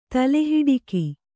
♪ tale hiḍiki